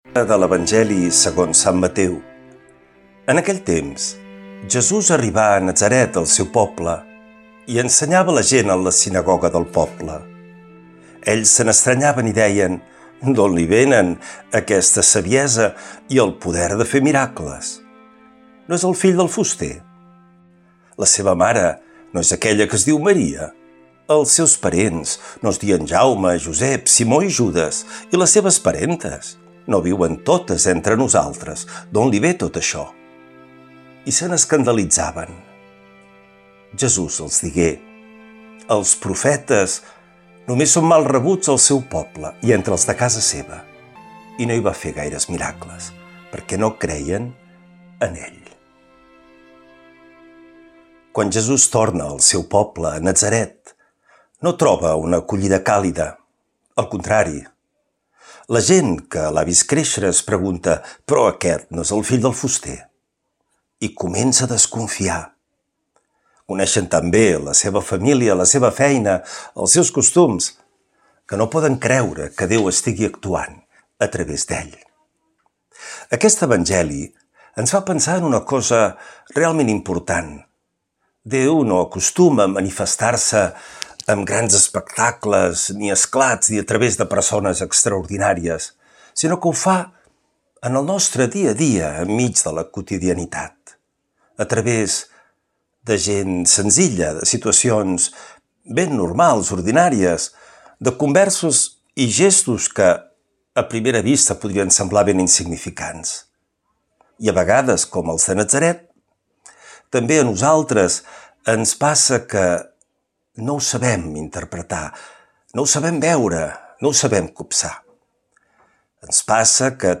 L’Evangeli i el comentari de divendres 01 agost del 2025.
Lectura de l’evangeli segons sant Mateu